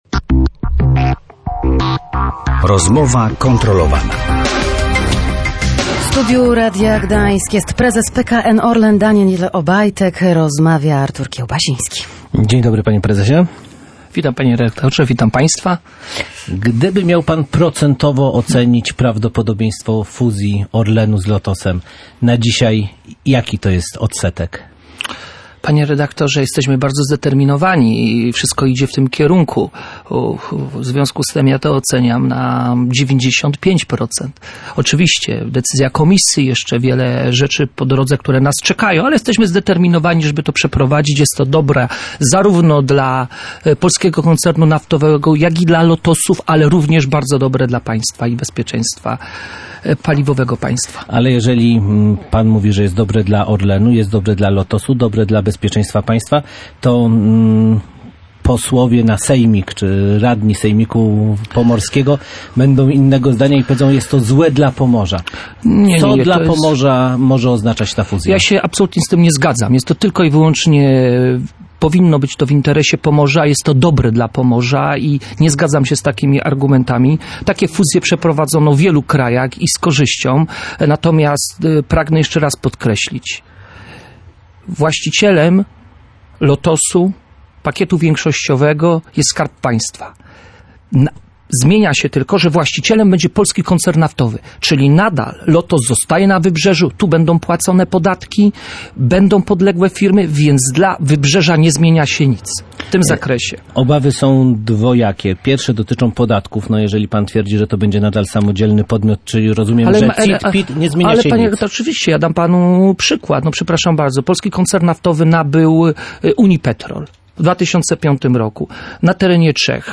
Daniel Obajtek zapewniał w Radiu Gdańsk, że fuzja Grupy Lotos z PKN Orlen będzie korzystna dla Pomorza i dla samego Lotosu.